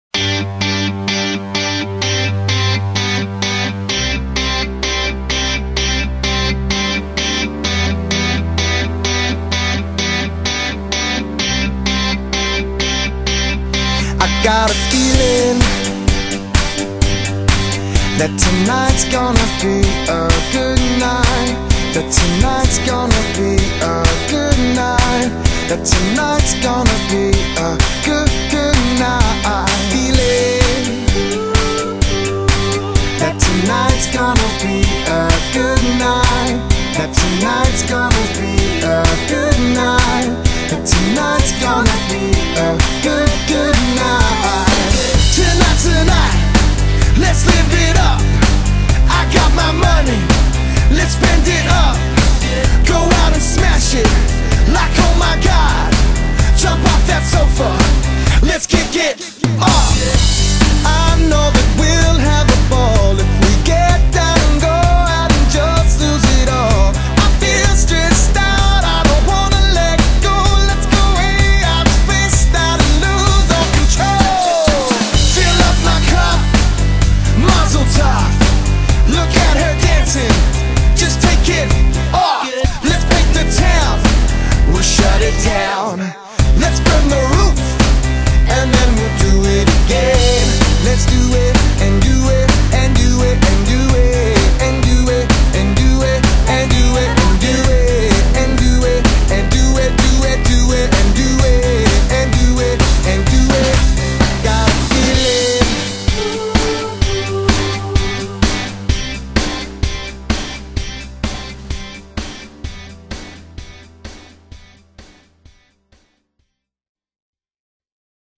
Melbourne’s No.1 Cover Band
guitar and vocals